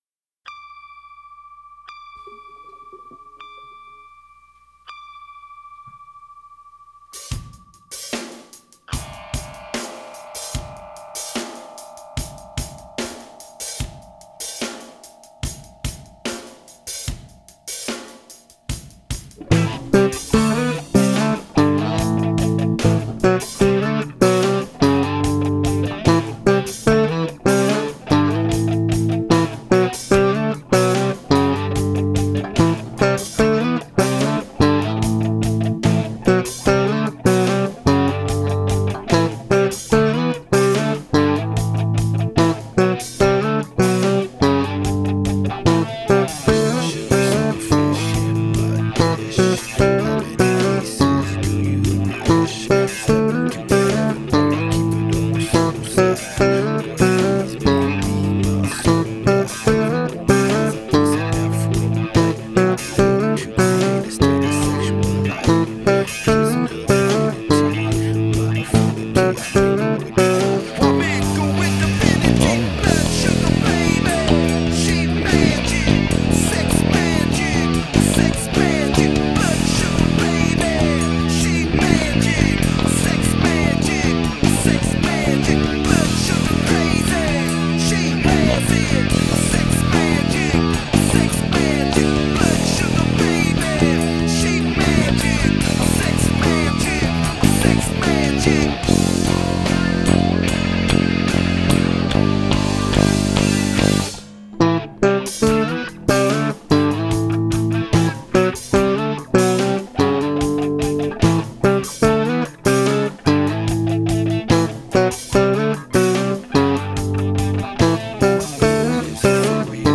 Instrumental / Bass only